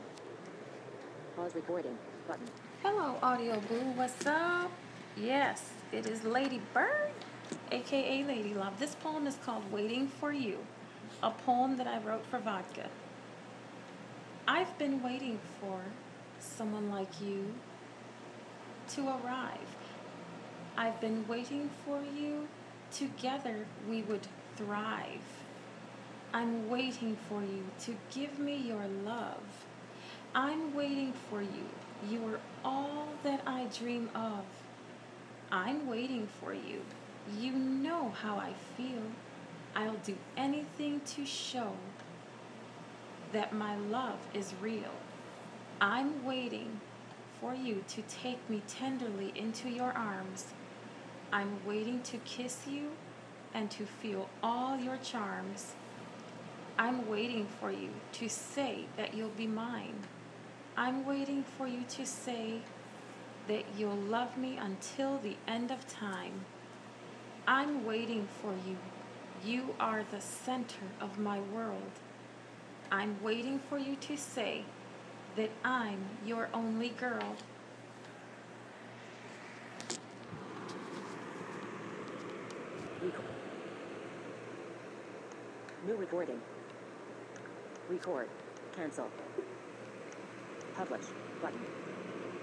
This is a poem written by me written for vodka I love a lifetime it's called waiting for you I hope you all enjoy it